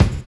NY 8 BD.wav